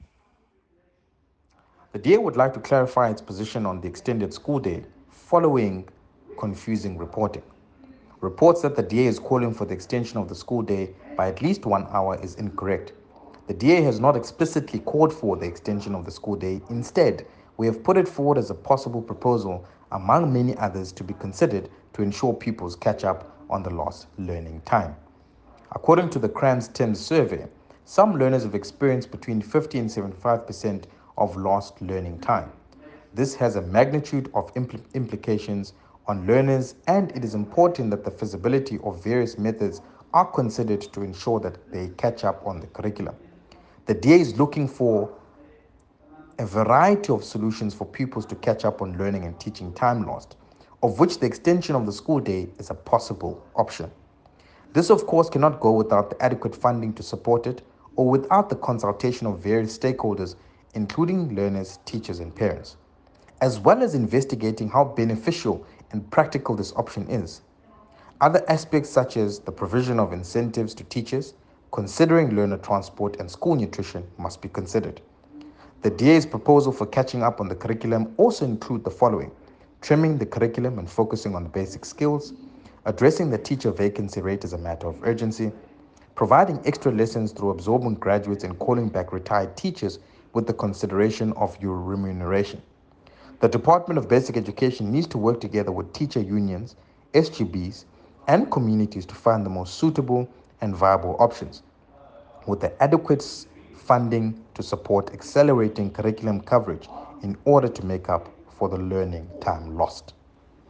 soundbite by Baxolile ‘Bax’ Nodada MP.